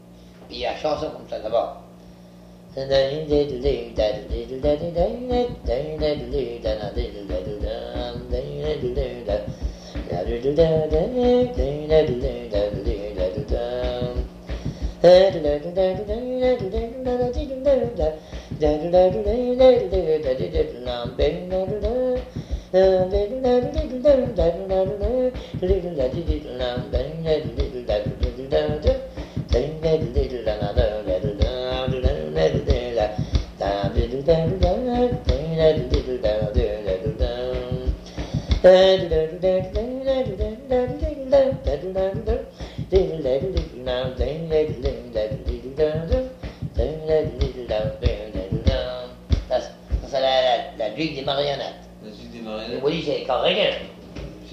La vue des marionnettes (turlutte) · ÉCHO-TNL — Encyclopédie des Chansons et de l'Héritage Oral de Terre-Neuve-et-Labrador